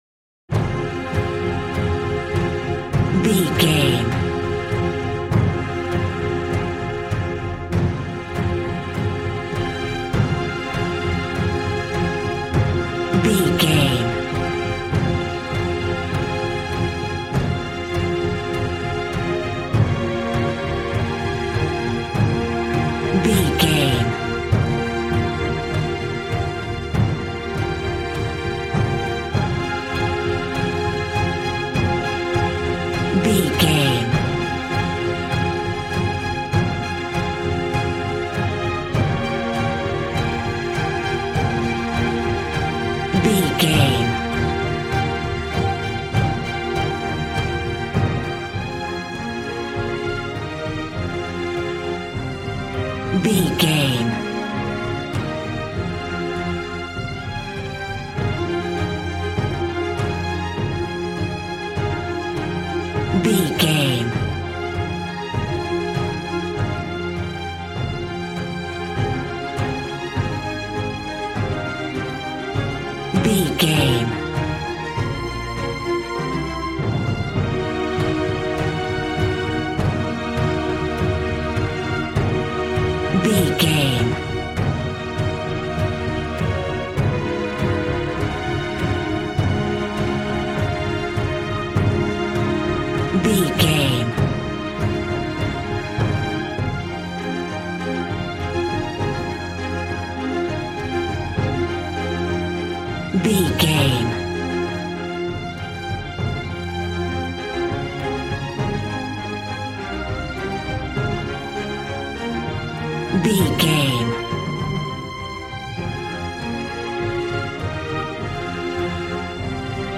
Aeolian/Minor
suspense
piano
synthesiser